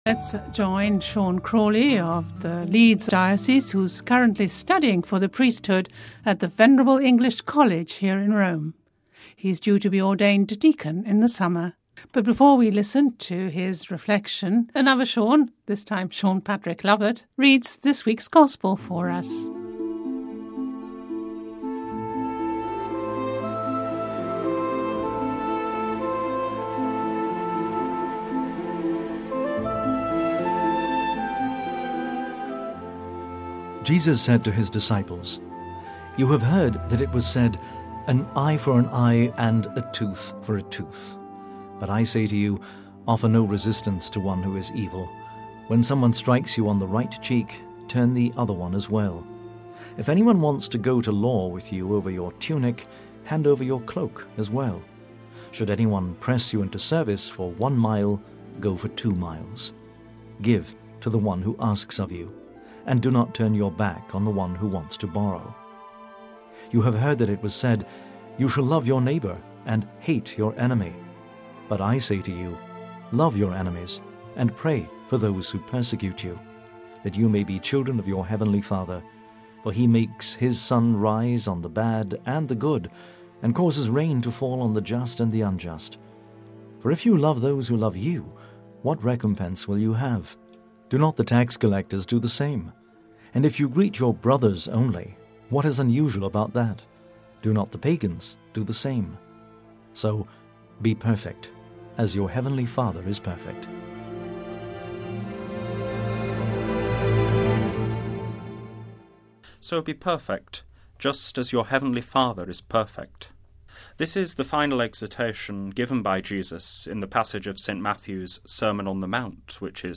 This week's reflection on the Sunday Gospel for the Seventh Sunday of the Year